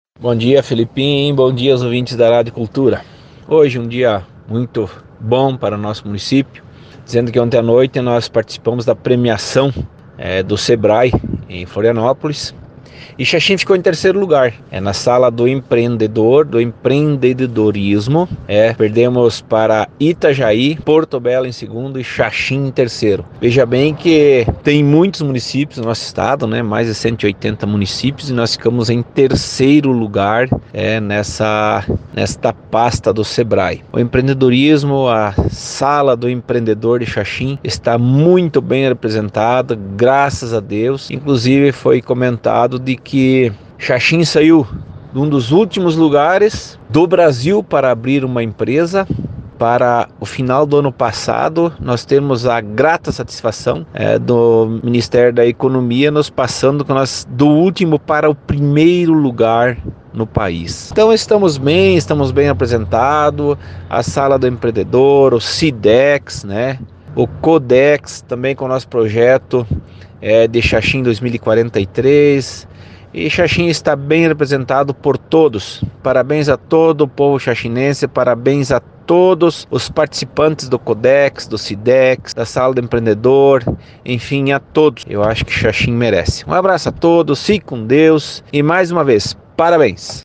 PREFEITO-CHICO-FOLLE-30-04.mp3